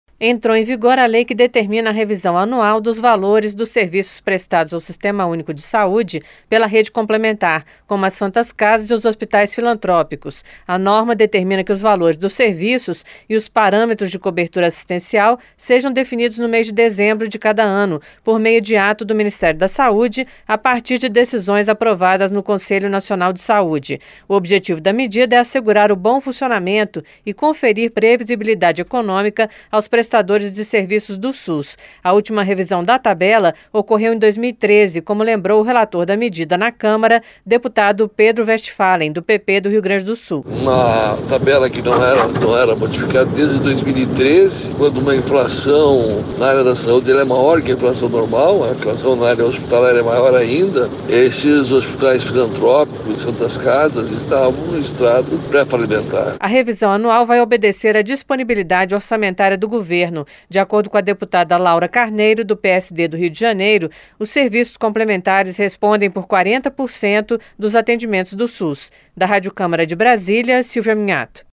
NOVA LEI GARANTE REAJUSTE ANUAL DOS SERVIÇOS PRESTADOS PARA O SUS. OS DETALHES COM A REPÓRTER